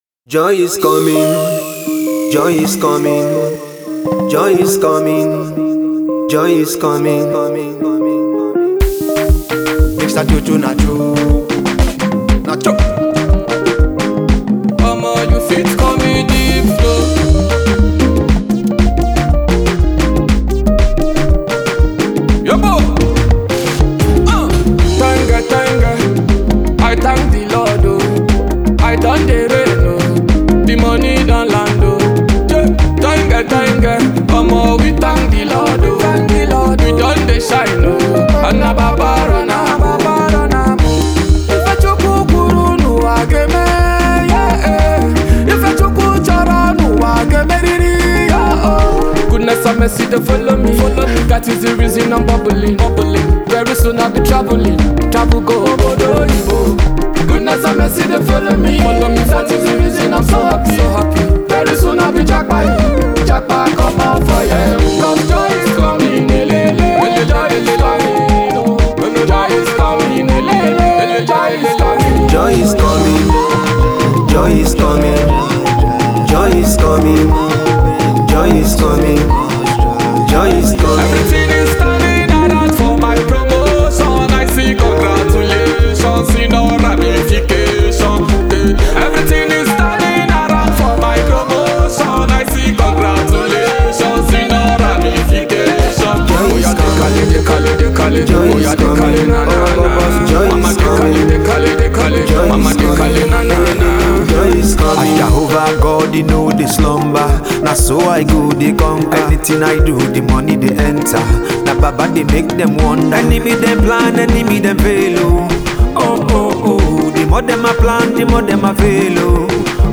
a prayer of faith from a soul filled with extreme sadness